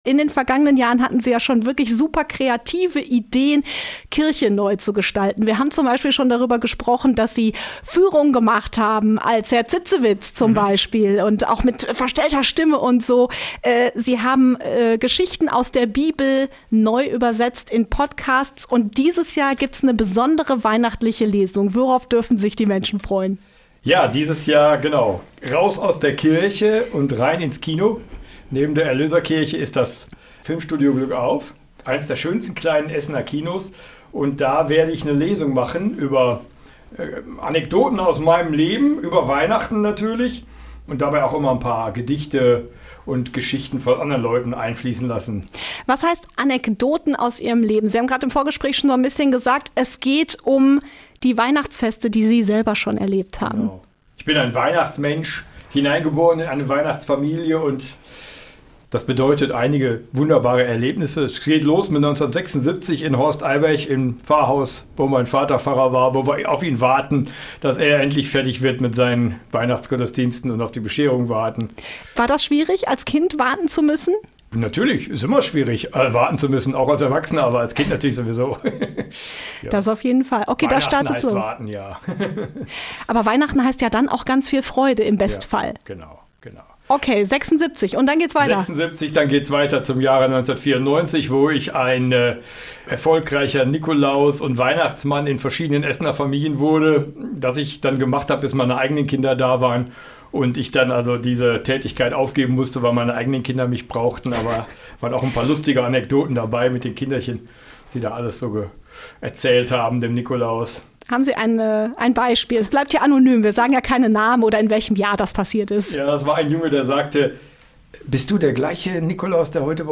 Weihnachtslesung in Essen: Pfarrer erzählt Anekdoten aus der Weihnachtszeit im Glückauf - Radio Essen